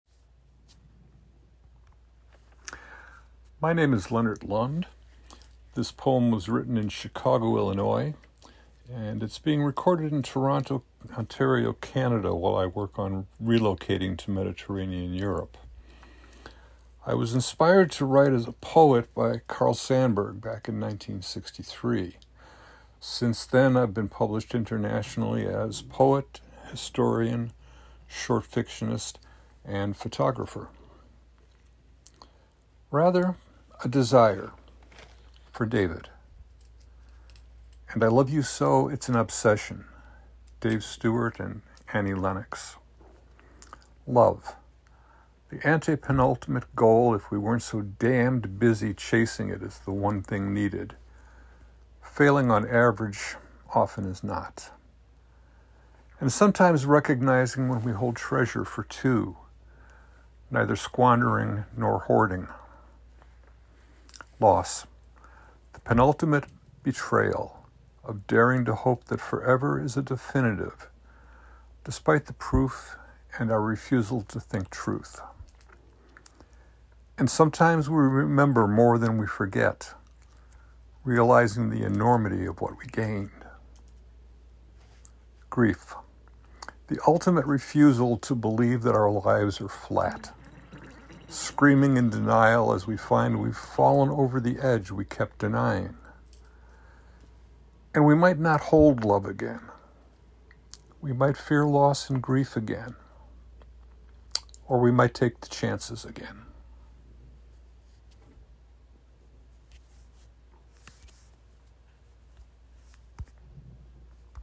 You can listen to the author read this poem by clicking the below arrow: